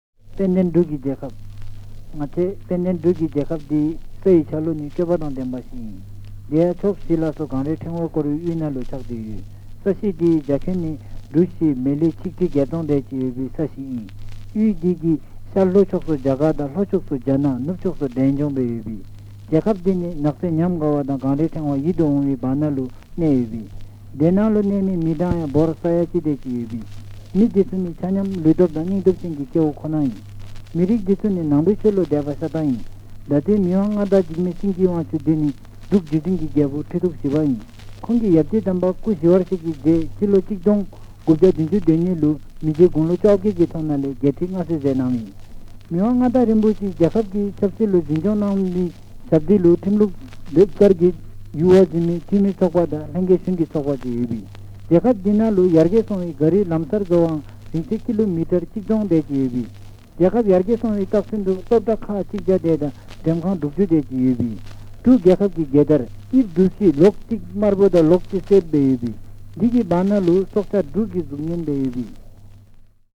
These tiny records – possibly the smallest vinyl records that can still be played with a stylus – have an adhesive backside to affix to either a letter to postcard.